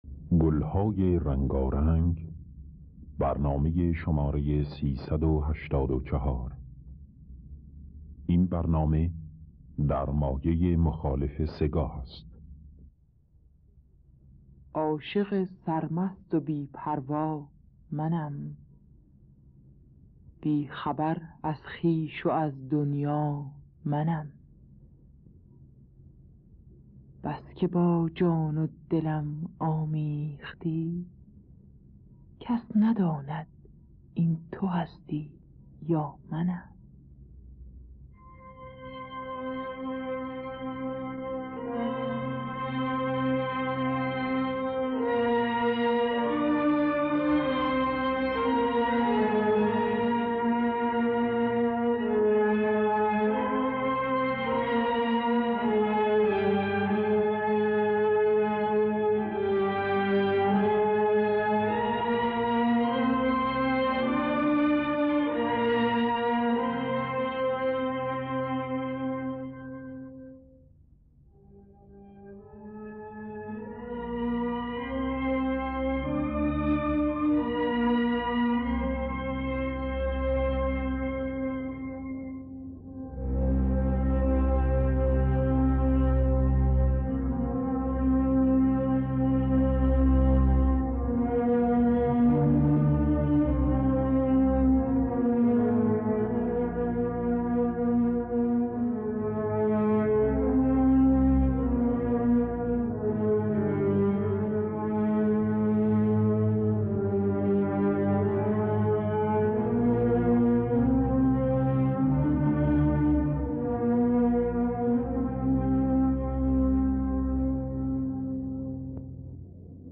گلهای رنگارنگ ۳۸۴ - سه‌گاه
خوانندگان: الهه حسین قوامی